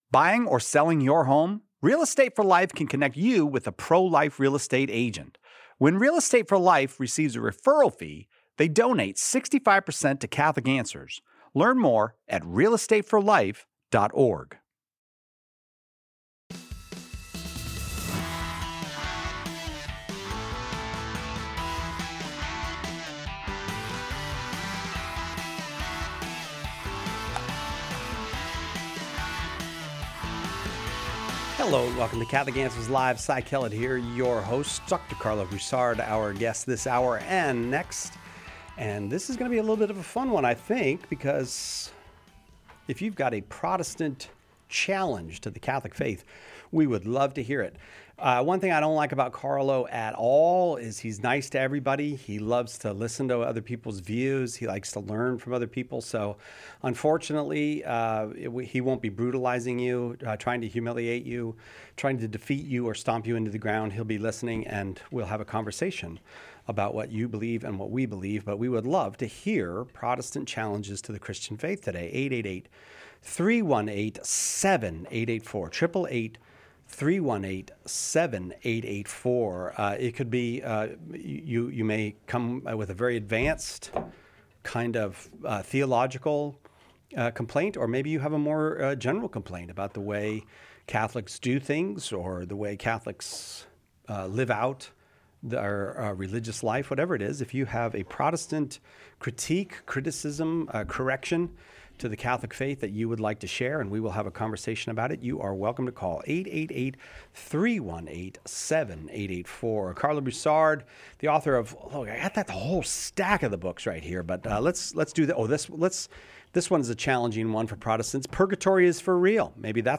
Today’s show answers caller’s questions regarding how to defend the Catholic faith. Additionally, the conversation touches on the historical context of Mary’s assumption and the challenges surrounding the book of Tobit, including its implications on purity and divine inspiration.